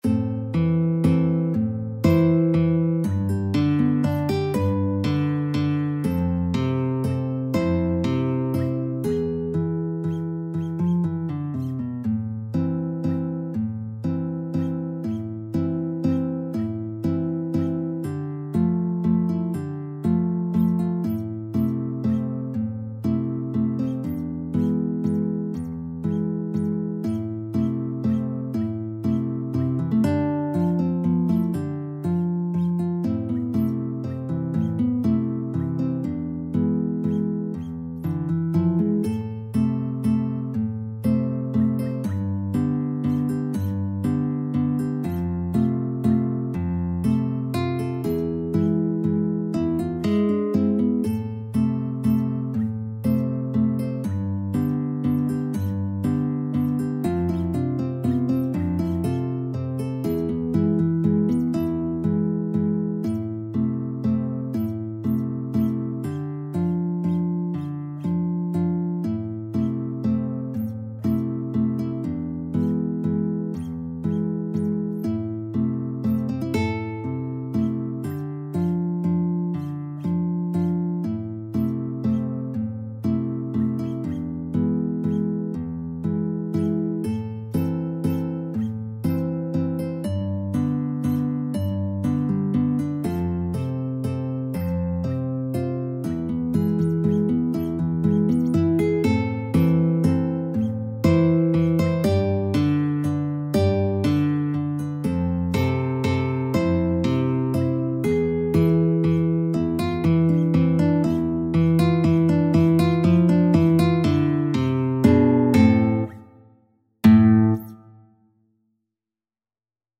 Guitar 1Guitar 2Guitar 3
3/4 (View more 3/4 Music)
A minor (Sounding Pitch) (View more A minor Music for Guitar Trio )
Slow Waltz .=40
Traditional (View more Traditional Guitar Trio Music)